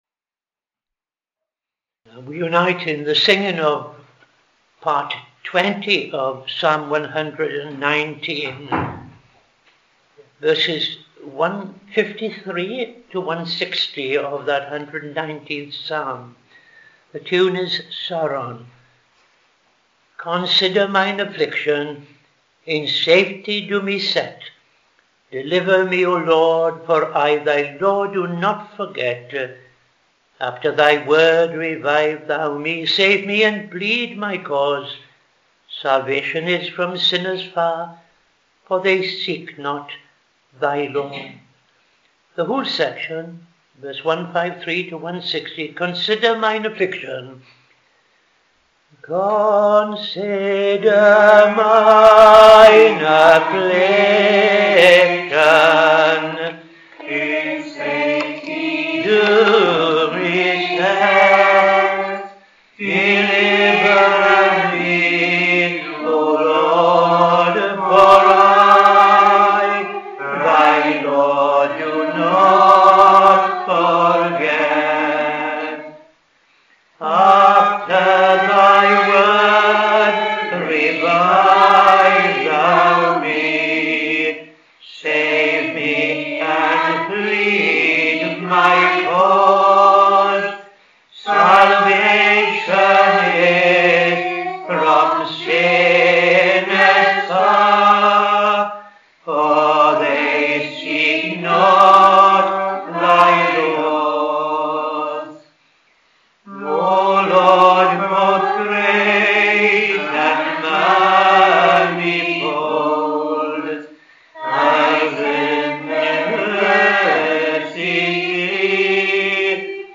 5.00 pm Evening Service Opening Prayer and O.T. Reading I Chronicles 6:49-81
Psalm 146:1-6 ‘Praise God. The Lord praise, O my soul.’ Tune Hamilton